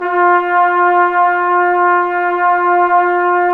Index of /90_sSampleCDs/Roland L-CD702/VOL-2/BRS_Flugel Sect/BRS_Flugel Sect